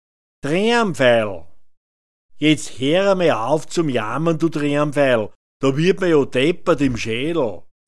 Treamveidl [‚dreamfeidl] m